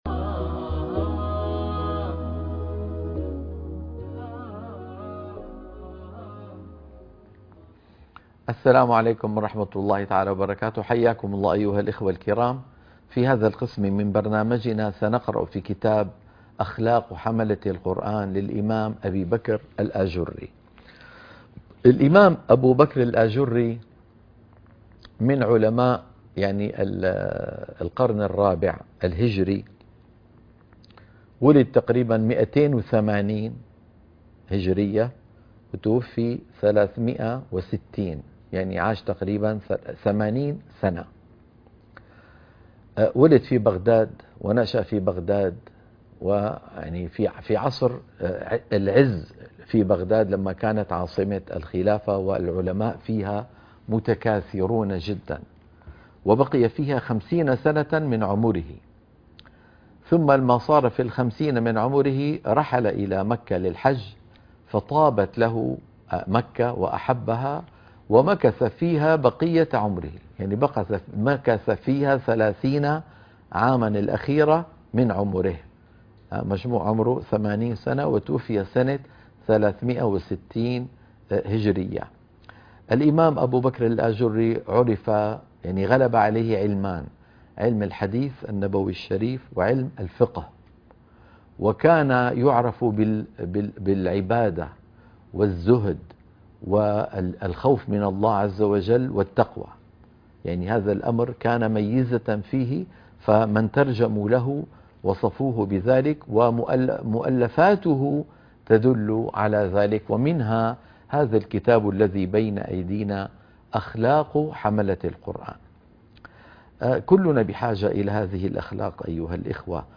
قراءة كتاب أخلاق حملة القرآن - الحلقة الأولى - مقدمة الكتاب